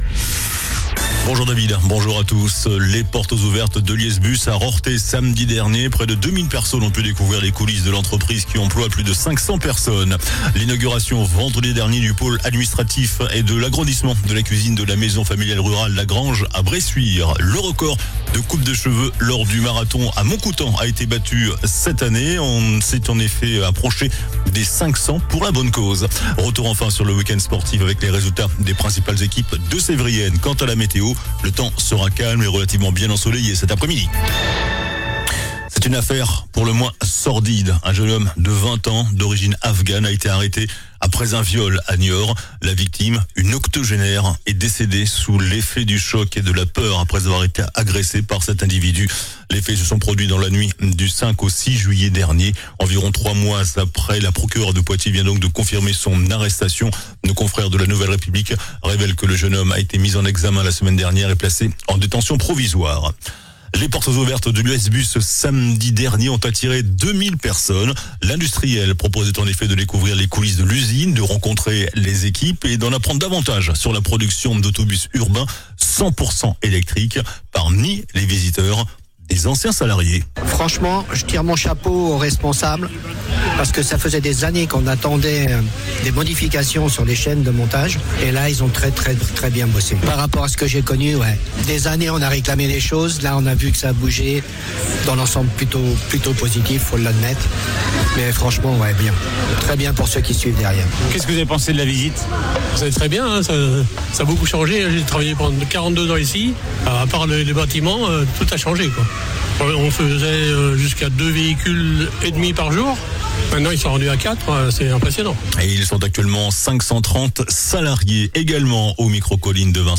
JOURNAL DU LUNDI 29 DEPTEMBRE ( MIDI )